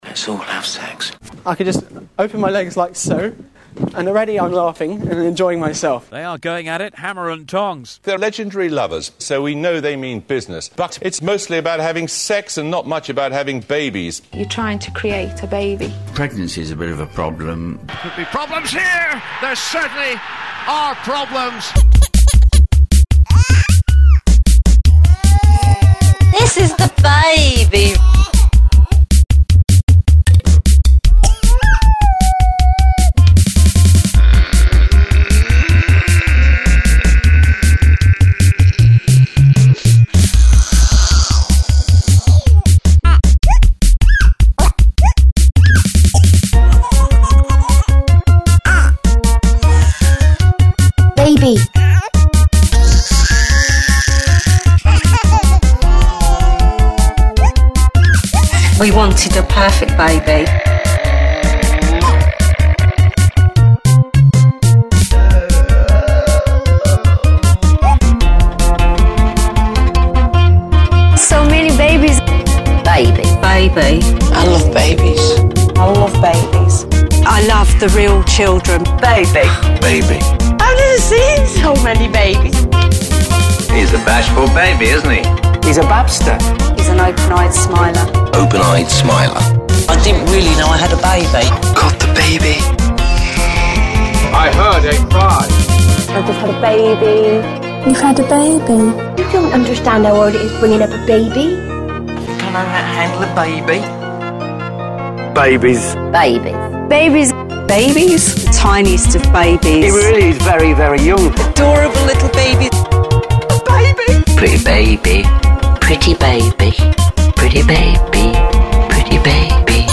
Genre: Electronic, Non-Music
Style: Comedy, Experimental, Musique Concrète